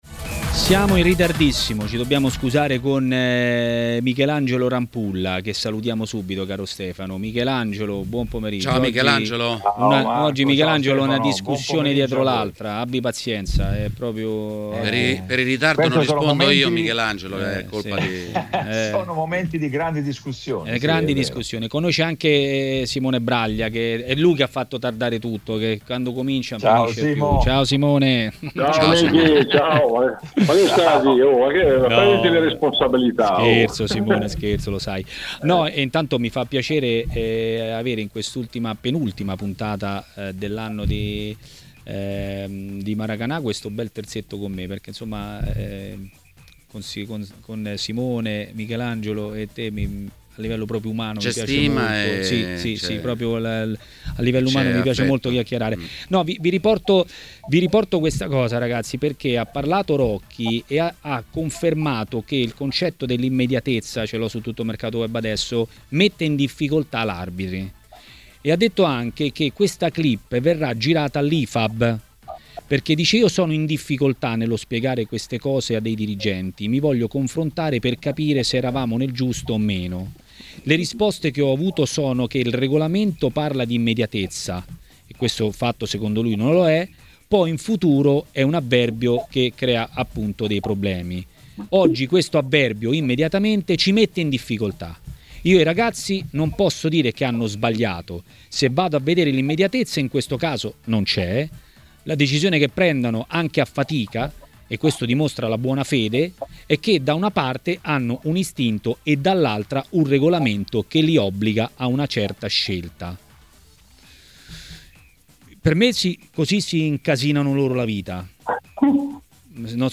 A parlare dei temi di giornata a Maracanà, trasmissione di TMW Radio, è stato l'ex portiere Michelangelo Rampulla.